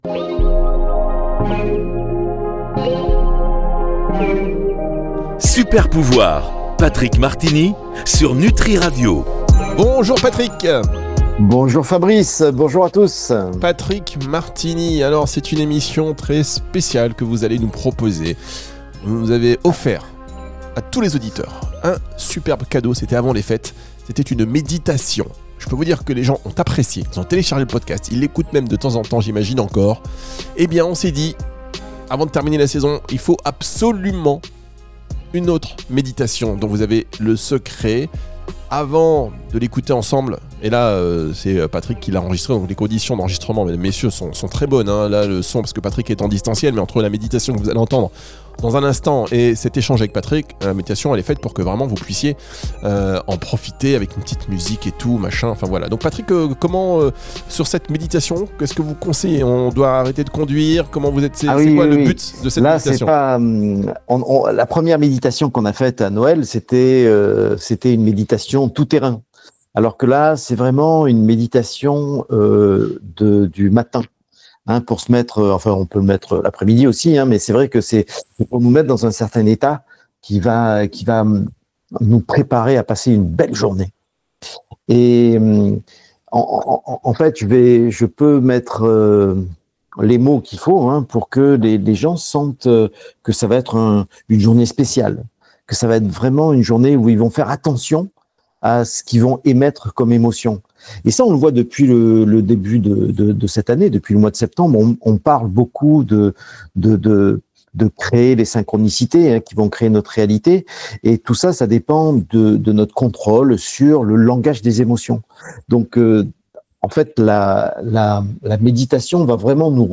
Emission spéciale: Séance de méditation du matin